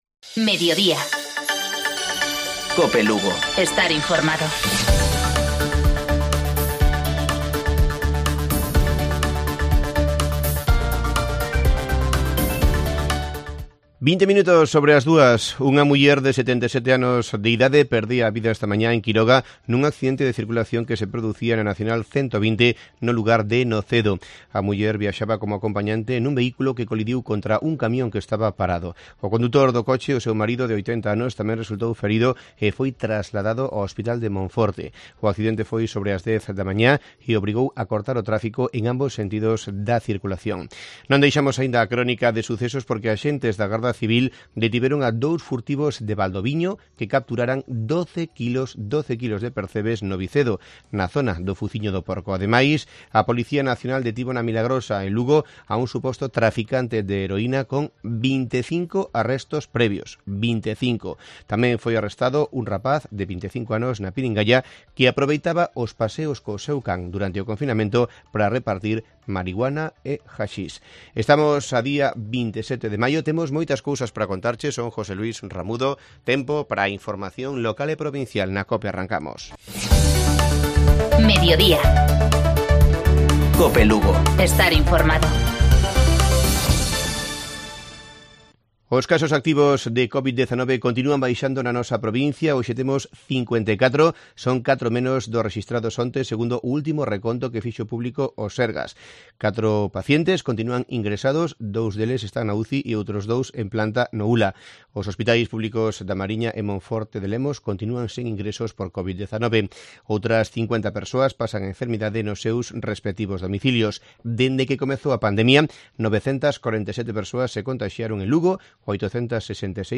Informativo Mediodía Cope. Miércoles, 27 de mayo. 14:20-14-30 horas.